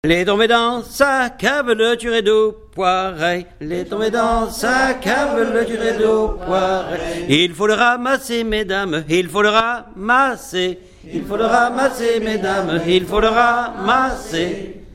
Couplets à danser
danse : branle : courante, maraîchine
Pièce musicale inédite